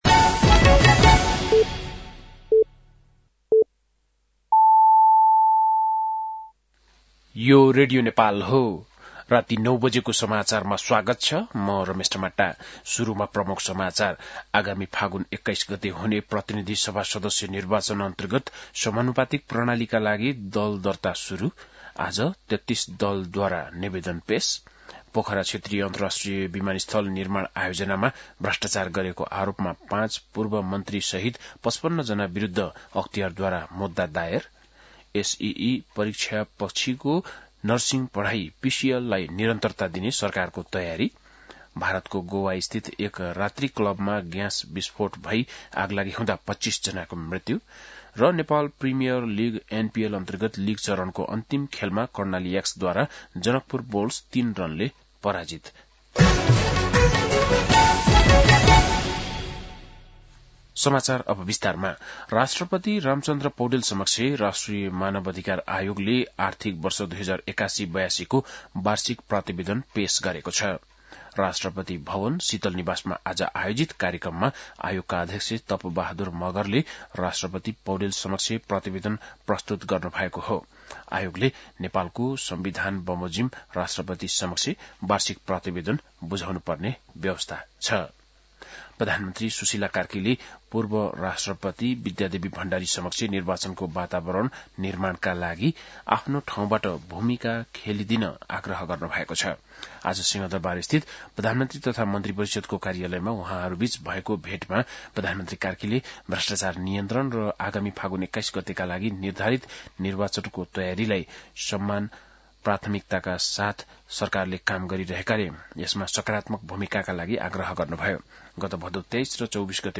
बेलुकी ९ बजेको नेपाली समाचार : २१ मंसिर , २०८२
7-pm-nepali-news-8-21.mp3